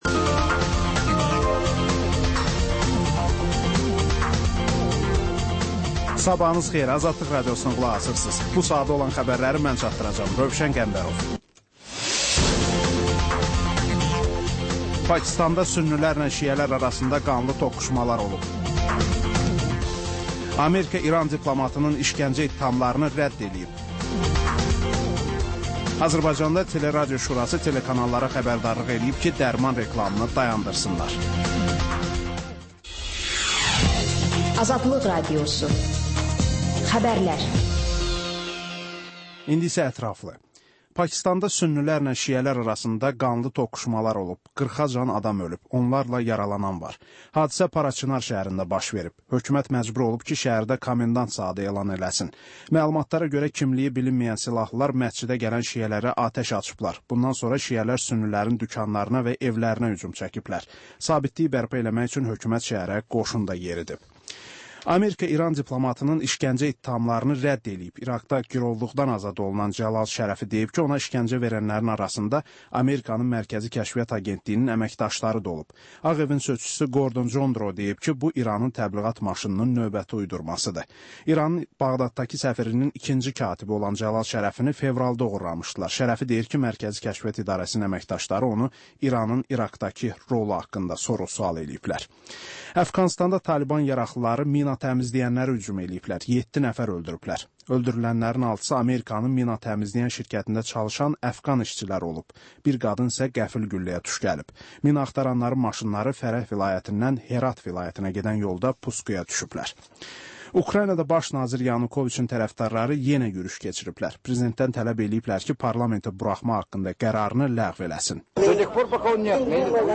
Hadisələr, reportajlar. Panorama: Jurnalistlərlə həftənin xəbər adamı hadisələri müzakirə edir. Və: Qafqaz Qovşağı: Azərbaycan, Gürcüstan və Ermənistandan reportajlar.